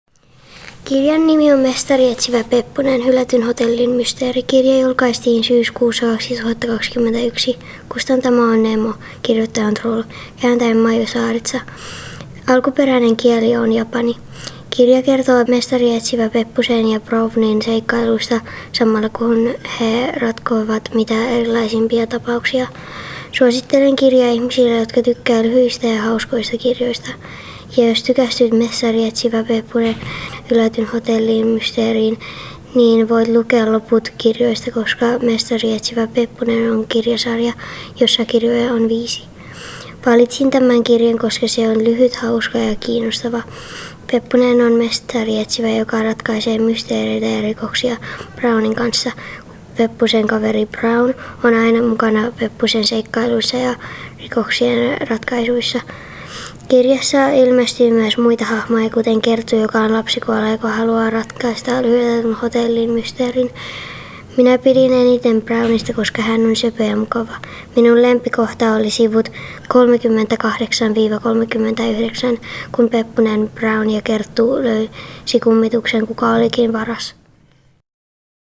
Kirjaesittely: Mestarietsivä Peppunen – Hylätyn hotellin mysteeri | radiofiskars